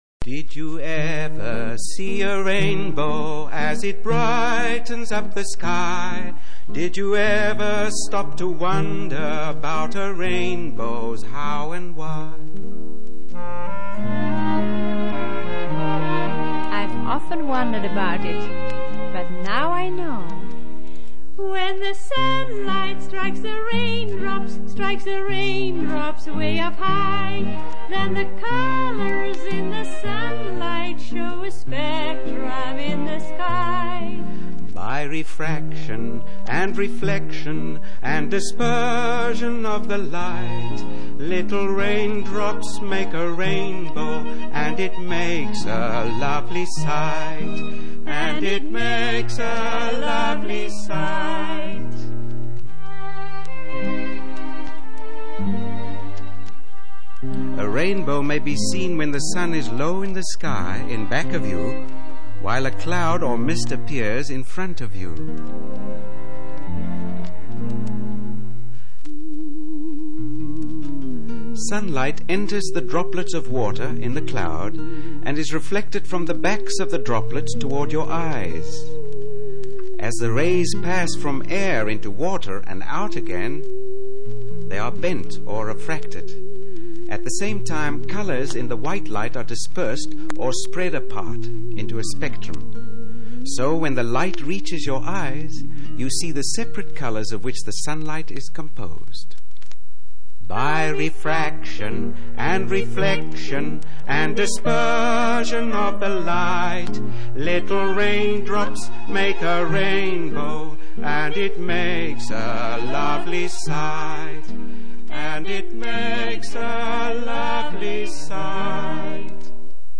song about dispersion creating rainbows.